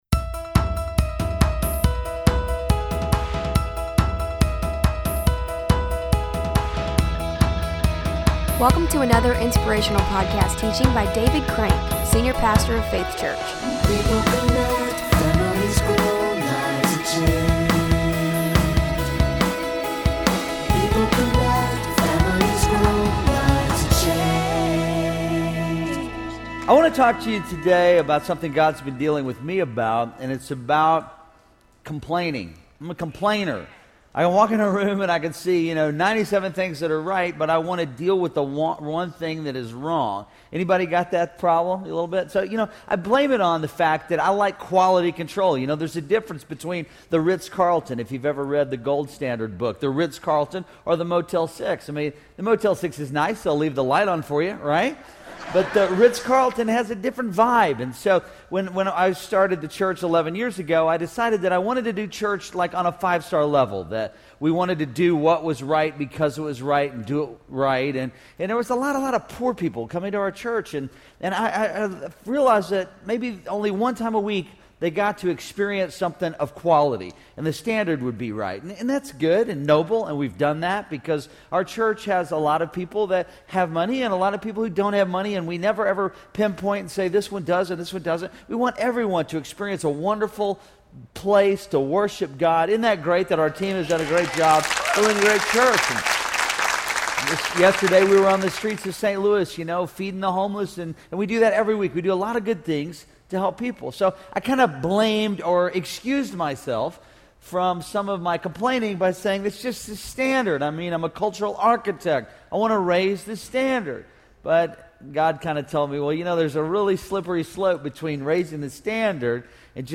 Episode from Faith Church Audio Podcast